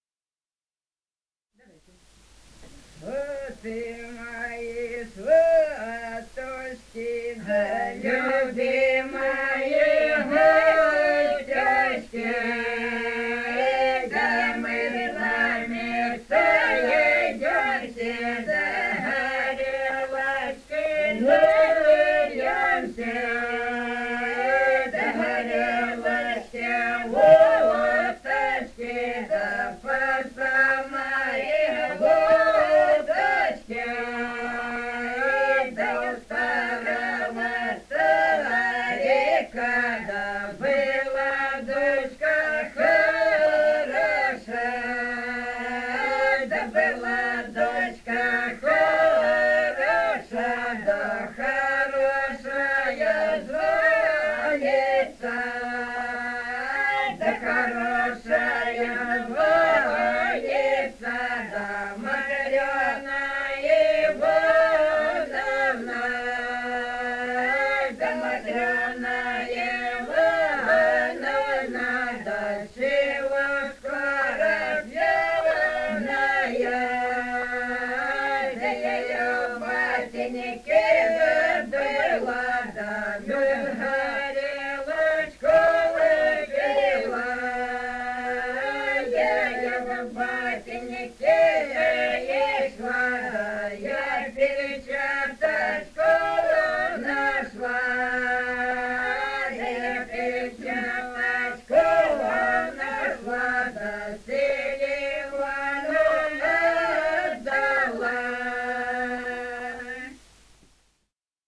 «Сваты мои, сваточки» (свадебная).
Ростовская область, г. Белая Калитва, 1966 г. И0940-12